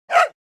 dogbark.wav